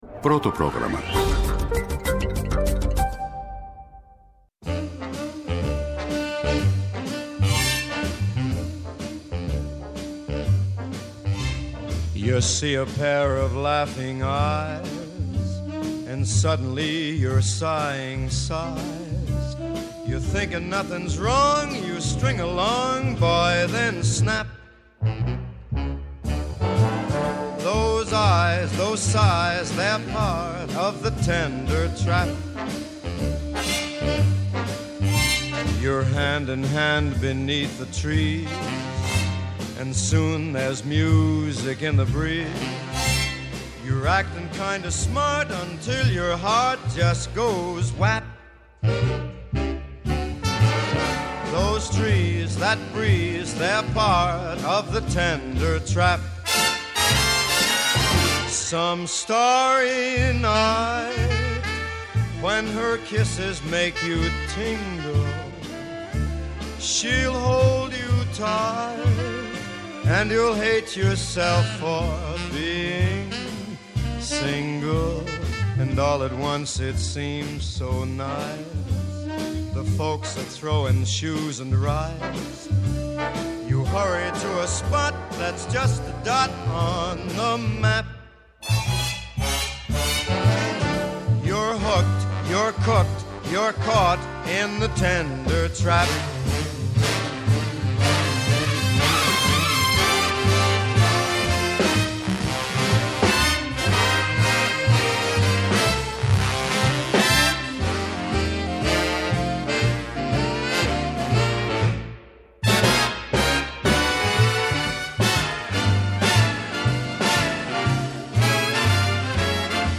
Καλεσμένοι σήμερα στην εκπομπή “Ναι μεν Αλλά”:
-O Ανδρέας Λυκουρέντζος, πρόεδρος του ΕΛΓΑ.